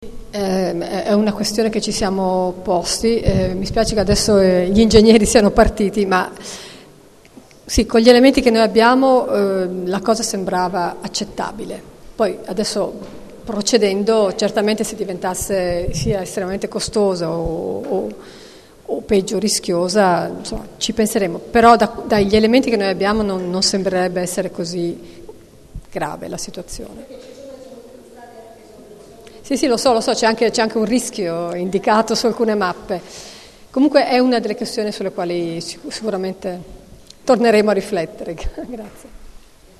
Piano di recupero del Quartiere Città della Scienza - Ascolto audio dell'incontro